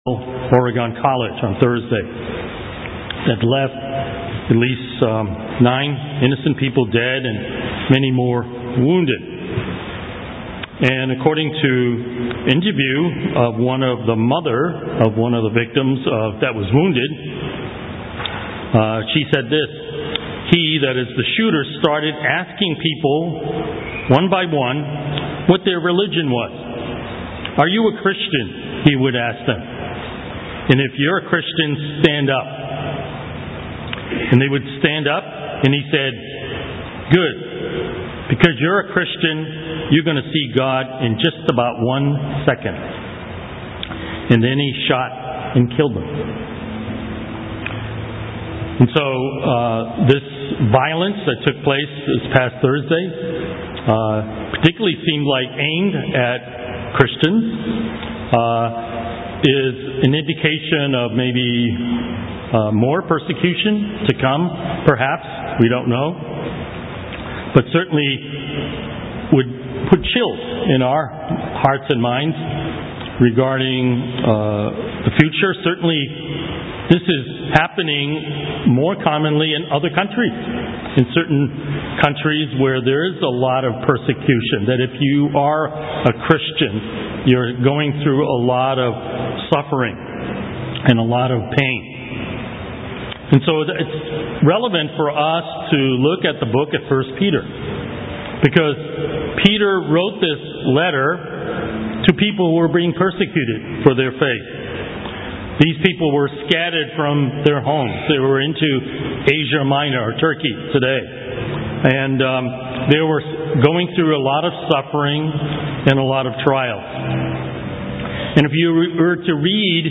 Note: Due to technical difficulties, sermon was recorded in 3 parts